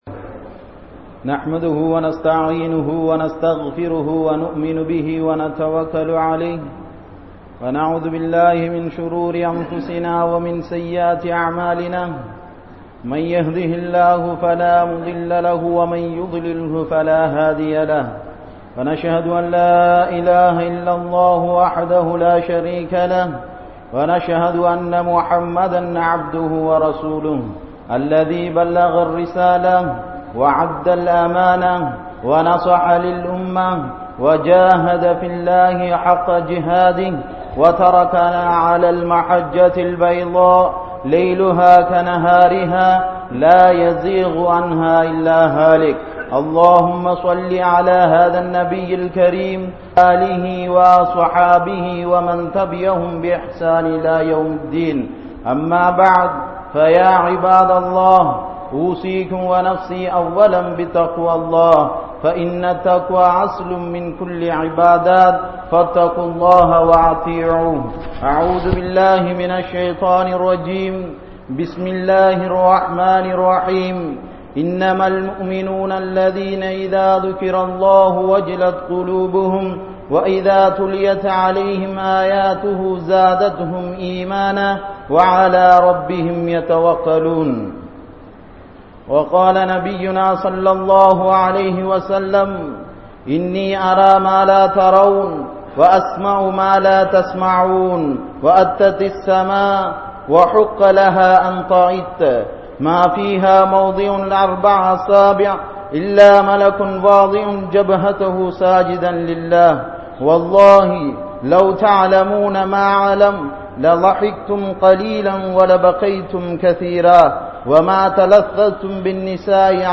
Allah`vukaaha Alutha Kangal | Audio Bayans | All Ceylon Muslim Youth Community | Addalaichenai